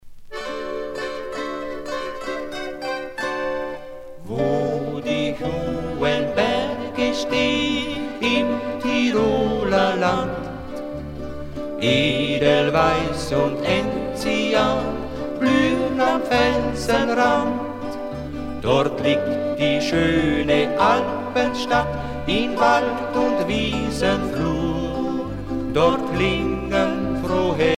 danse : valse lente
Pièce musicale éditée